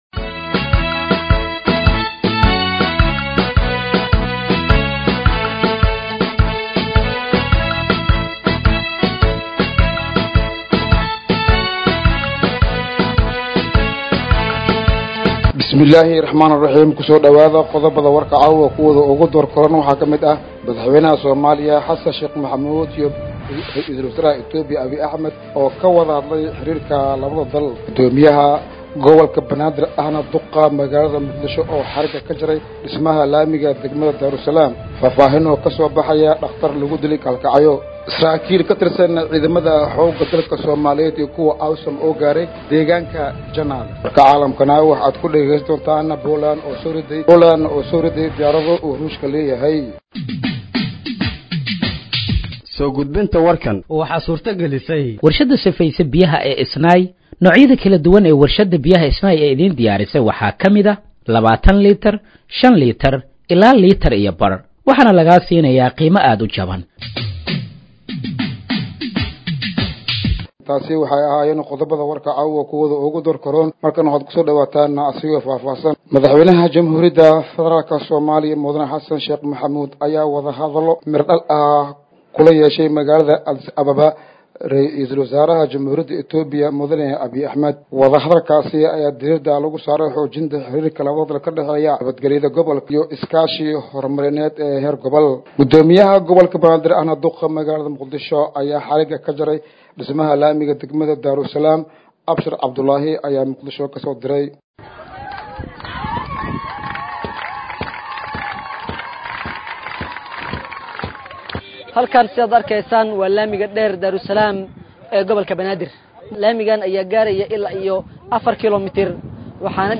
Dhageeyso Warka Habeenimo ee Radiojowhar 10/09/2025